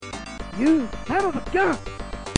I think he's supposed to be saying scum, but somehow I imagine he's been punched in the head so many times that he can't exactly talk properly |